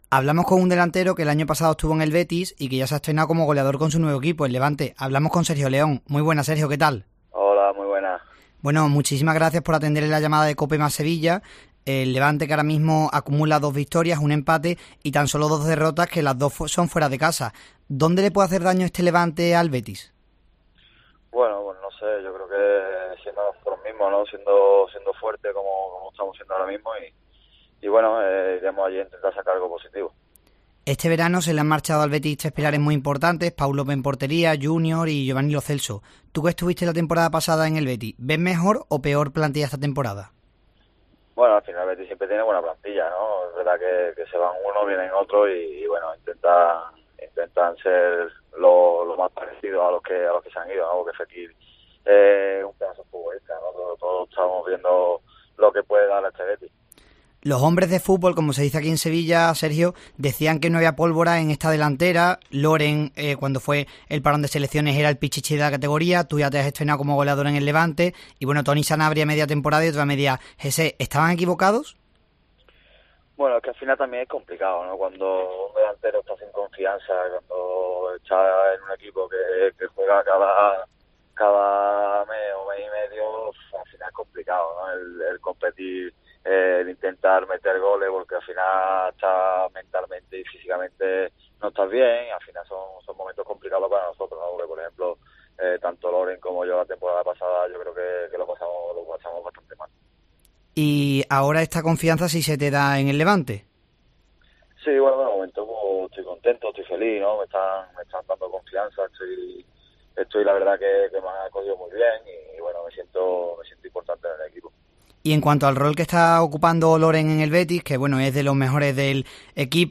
Sergio León entrevistado en Deportes Cope Sevilla en la previa del Betis - Levante